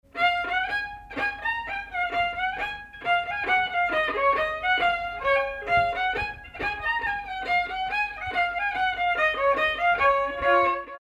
danse : marche
circonstance : bal, dancerie
Pièce musicale inédite